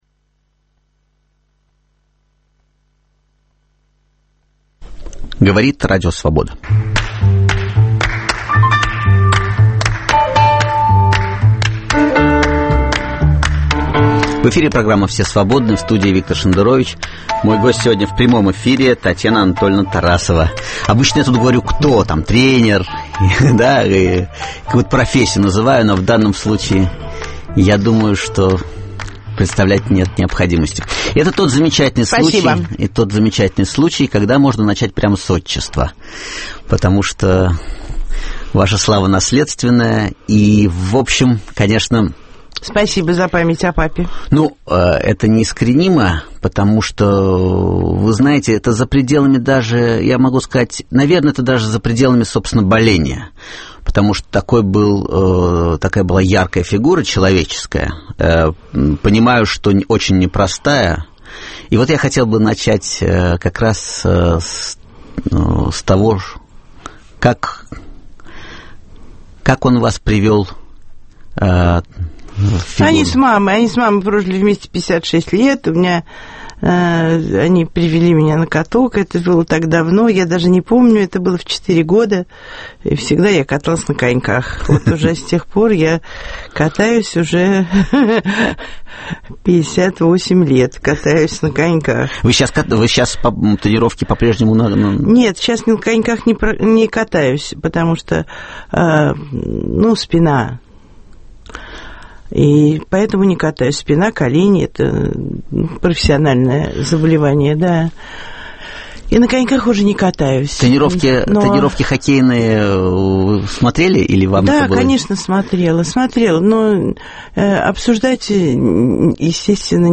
В гостях у Виктора Шендеровича – тренер по фигурному катанию Татьяна Тарасова.